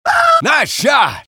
engineer_paincrticialdeath04.mp3